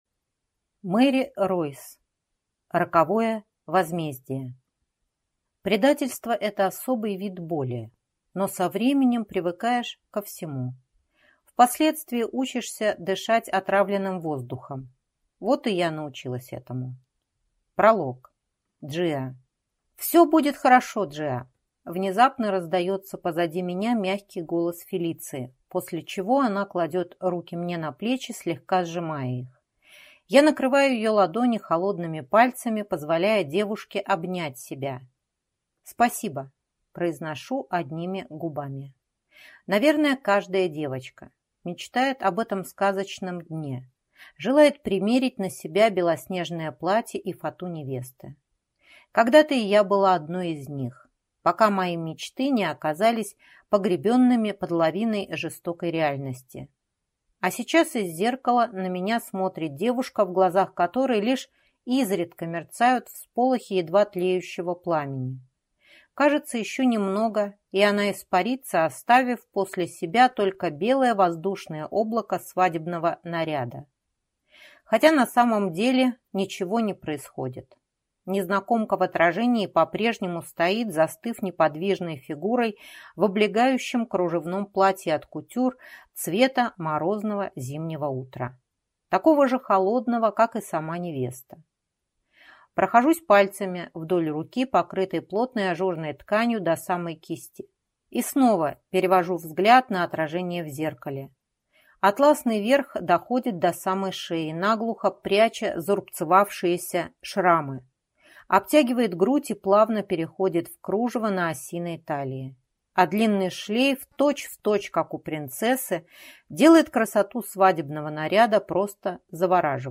Аудиокнига Роковое возмездие | Библиотека аудиокниг